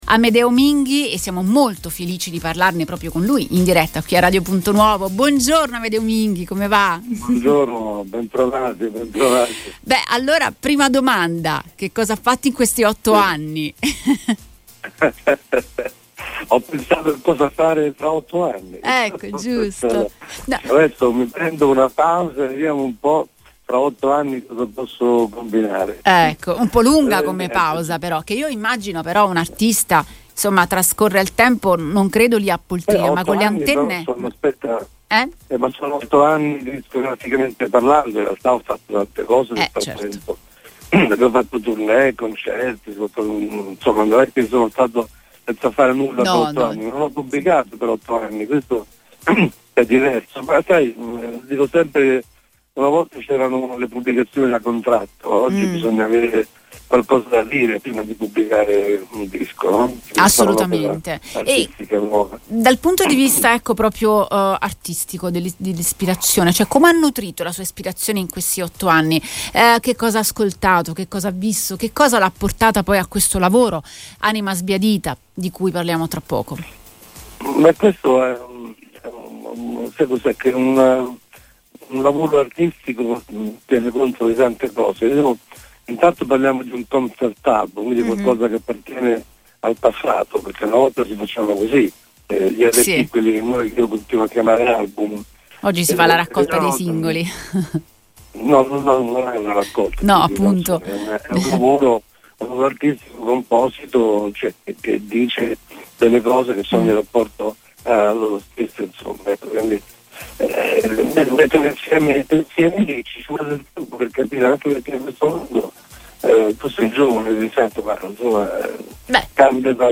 Dopo otto anni di assenza discografica, Amedeo Minghi torna sulle scene con Anima Sbiadita, un concept album che mescola nostalgia, riflessioni personali e uno sguardo critico sul presente. Durante un’intervista a Radio Punto Nuovo, l’artista ha raccontato il lungo percorso che lo ha portato a questo nuovo lavoro, rivelando aspetti inediti della sua ispirazione e della sua visione artistica.